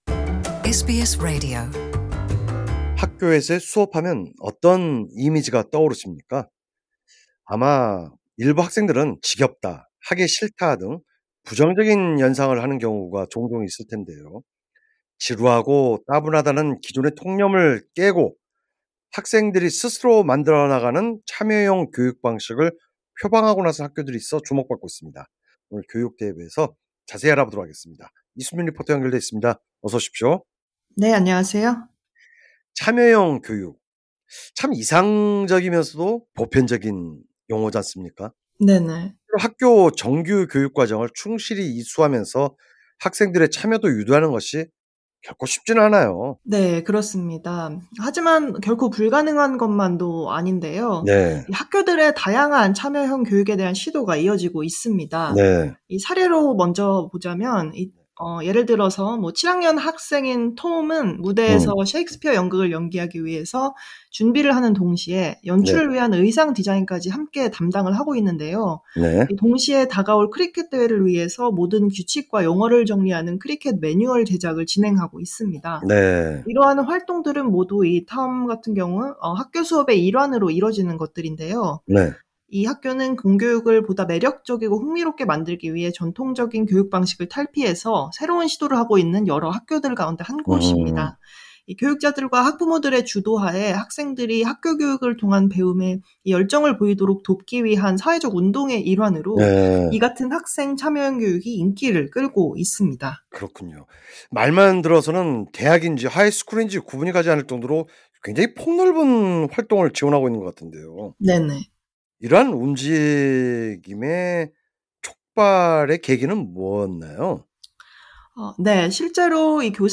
진행자: 학교 수업 하면 어떤 이미지가 떠오르시나요?
리포터: 네 그렇습니다.